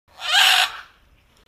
دانلود آهنگ جنگل 26 از افکت صوتی طبیعت و محیط
جلوه های صوتی
دانلود صدای جنگل 26 از ساعد نیوز با لینک مستقیم و کیفیت بالا